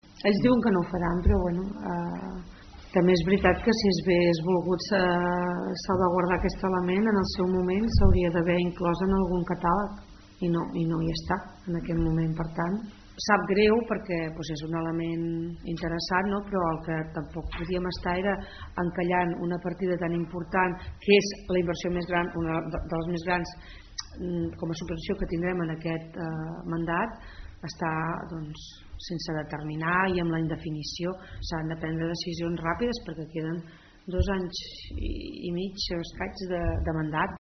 Neus Serra és la portaveu del govern de Malgrat de Mar.